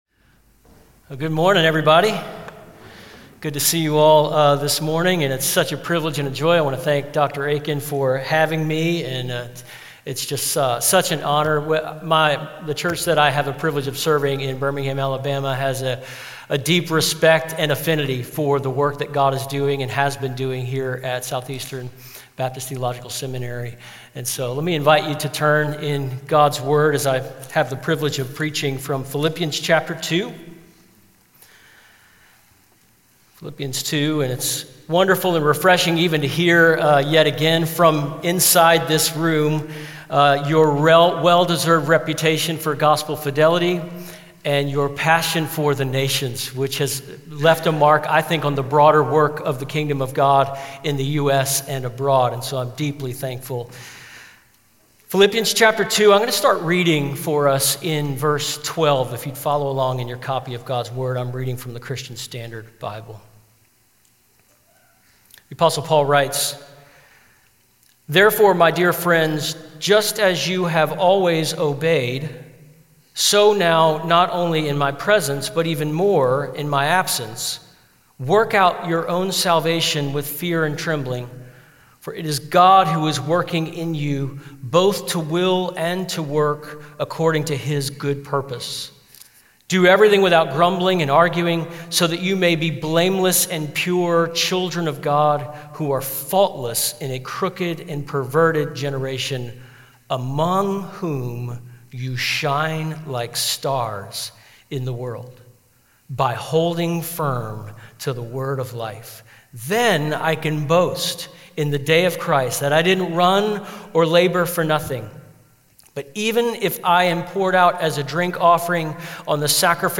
Southeastern Baptist Theological Seminary's Podcast consists of chapel and conference messages, devotionals and promotional information in both audio and video format.